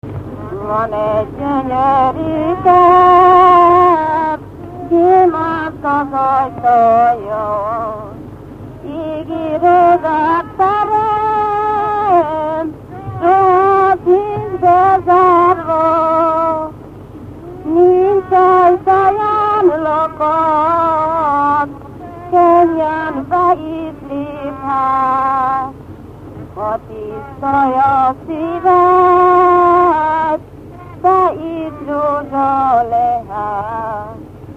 Dunántúl - Tolna vm. - Kéty
ének
Stílus: 9. Emelkedő nagyambitusú dallamok
Kadencia: 1 (5) 3 1